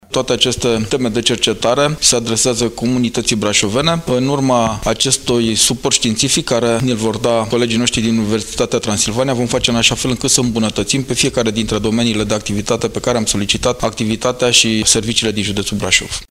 CJ Brașov va finanța 13 proiecte de cercetare pe care le va derula Universitatea Transilvania, în scopul rezolvării unor probleme ale comunității județului Brașov, arată președintele CJ Brașov, Adrian Veștea: